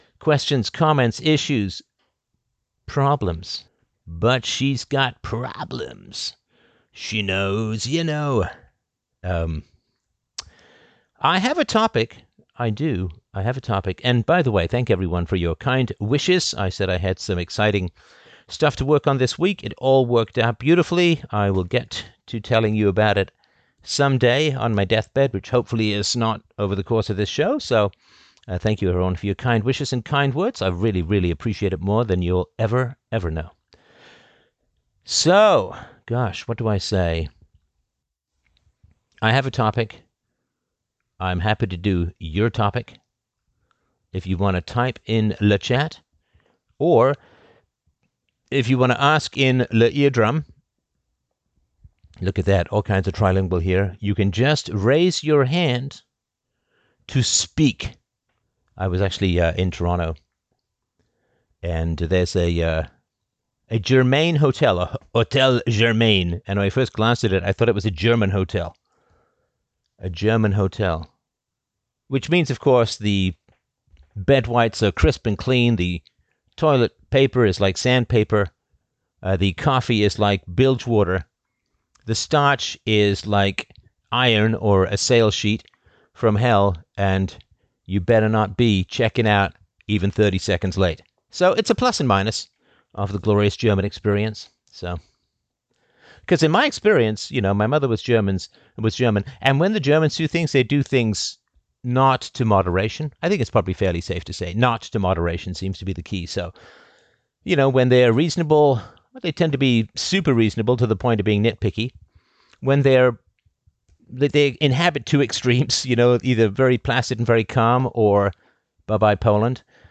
What's been your experience with inheritance? Livestreamed on October 17, 2023 Join the PREMIUM philosophy community on the web for free!